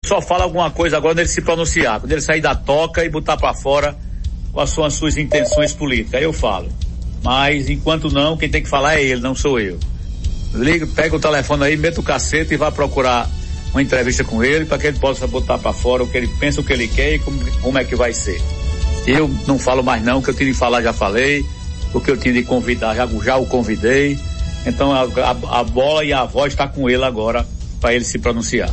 Durante entrevista nesta quinta-feira (15), Galdino afirmou que está aguardando um posicionamento claro de Rodrigues sobre suas intenções políticas.